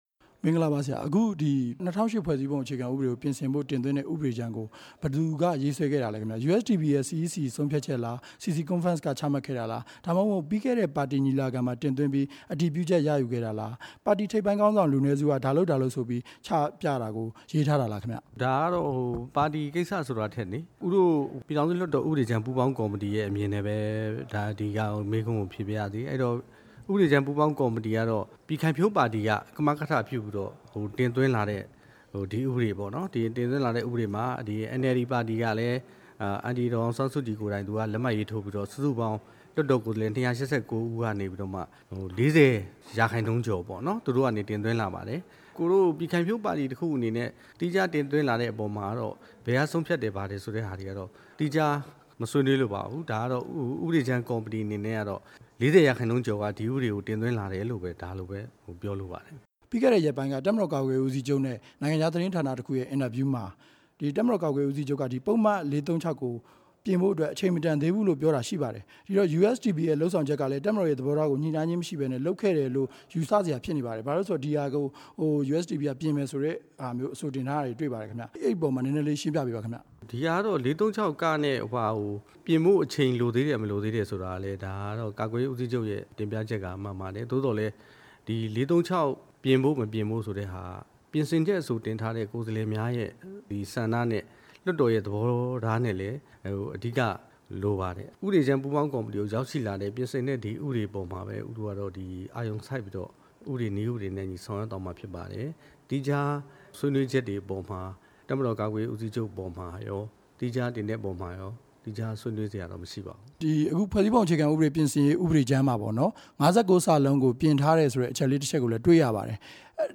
ဖွဲ့စည်းပုံအခြေခံဥပဒေ ပြင် ဆင်ရေးမူကြမ်းပြင်ဆင်ထားမှု အကြောင်း မေးမြန်းချက်